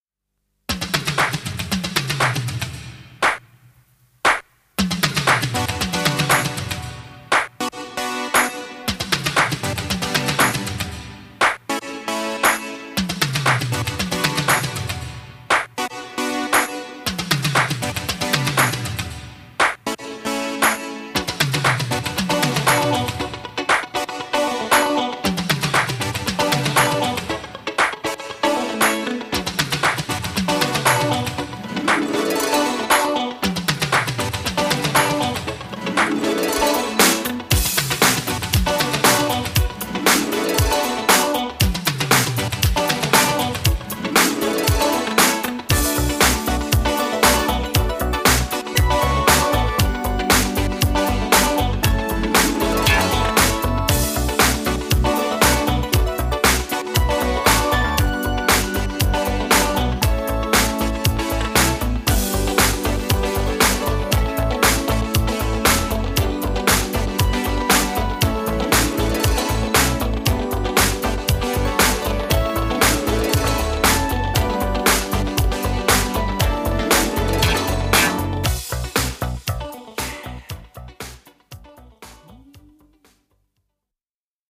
Podkład muzyczny (stereo)